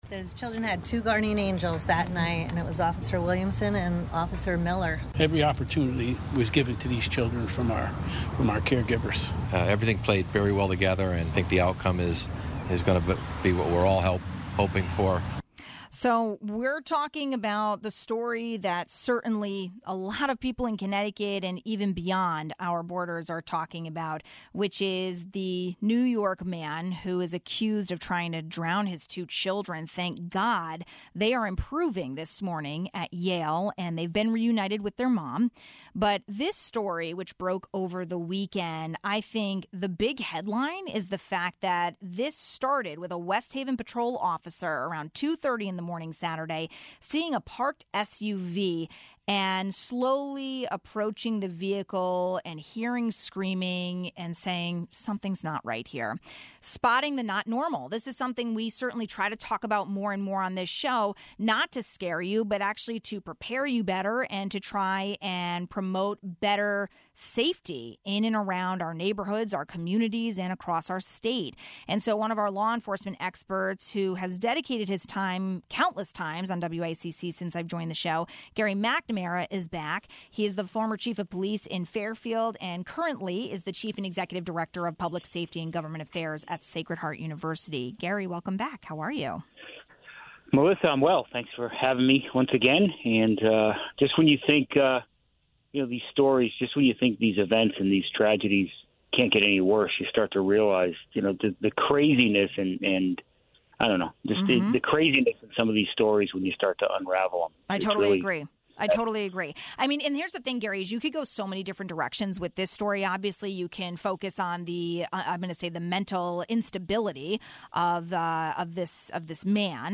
Two toddlers are on the road to recovery at Yale after their father allegedly tried to drown them at a West Haven beach. The twin victims are alive because of the brilliant, quick action done by a patrol officer. We spoke with law enforcement expert